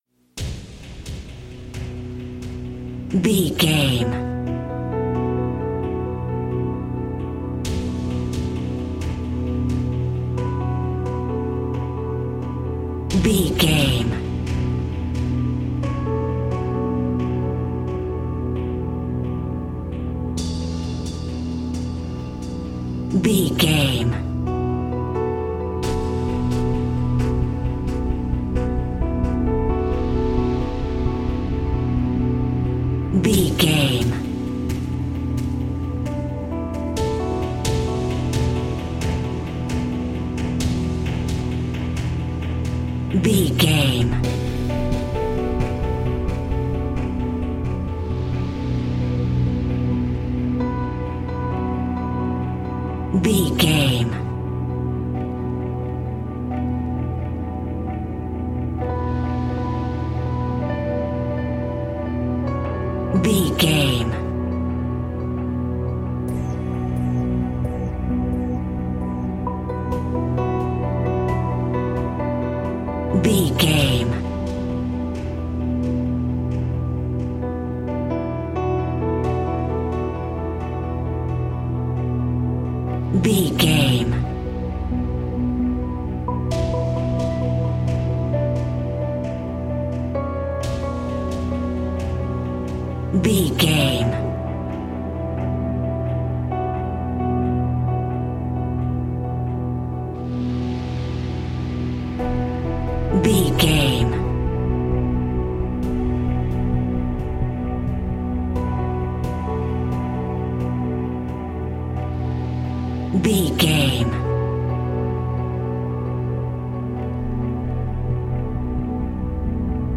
Aeolian/Minor
piano
synthesiser
drum machine